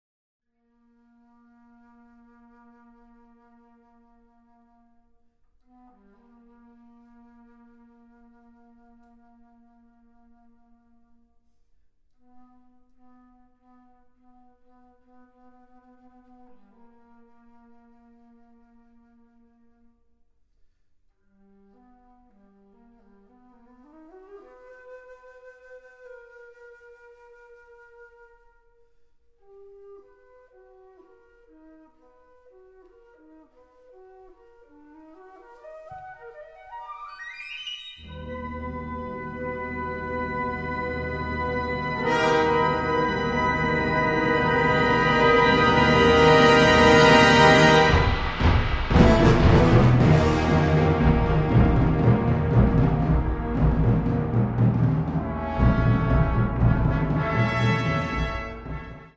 Unterkategorie Zeitgenössische Bläsermusik (1945-heute)
Besetzung Ha (Blasorchester)